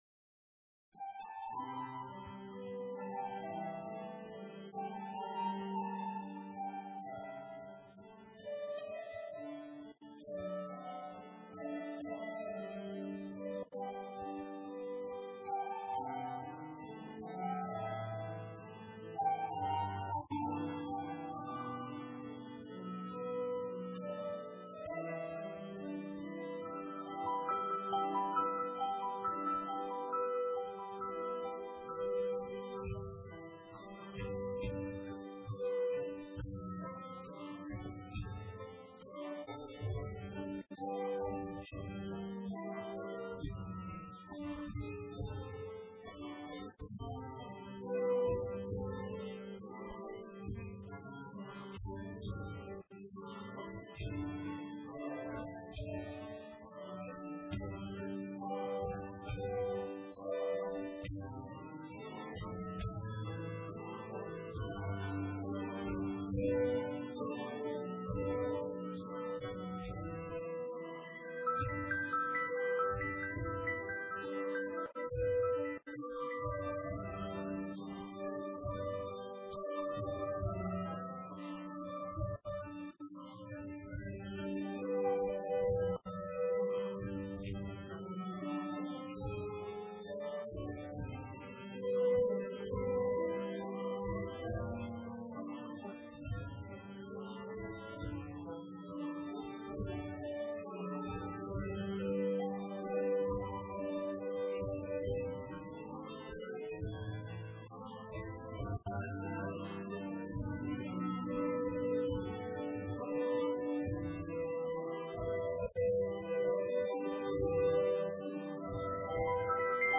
A take with voice Listen Song